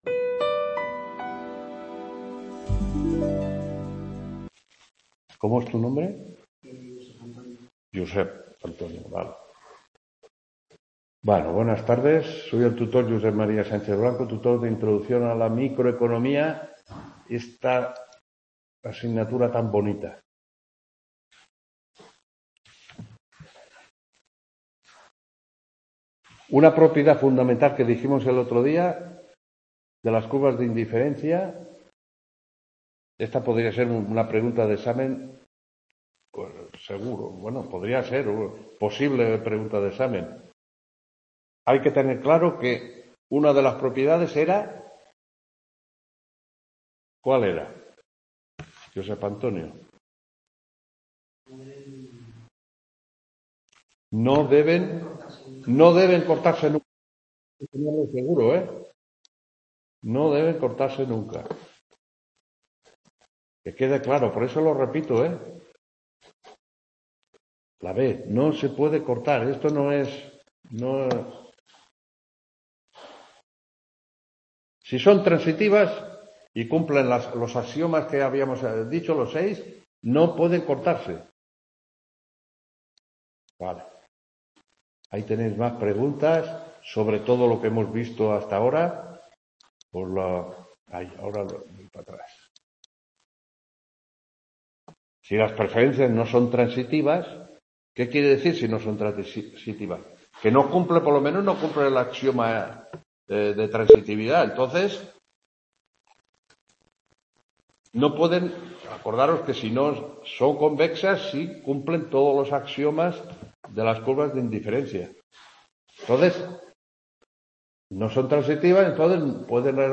4ª TUTORÍA INTRODUCCIÓN A LA MICROECONOMÍA 07-11-23 …